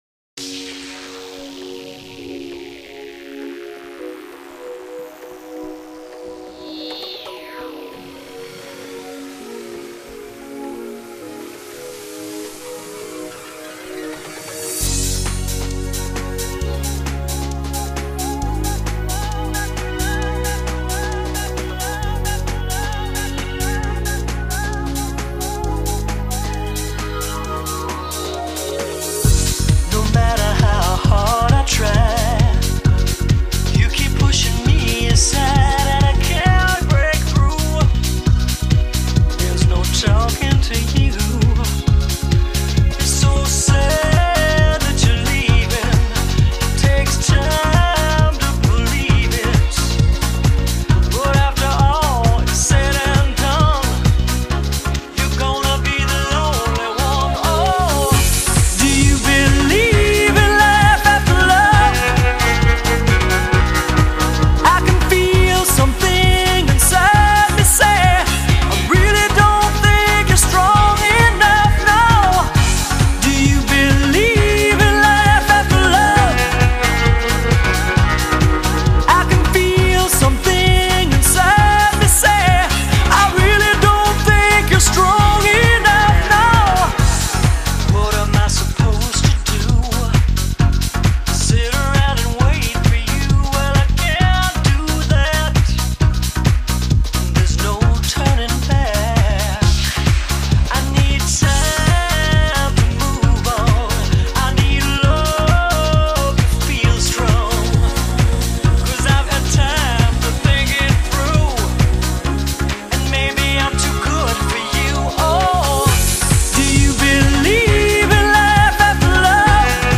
Pop, Dance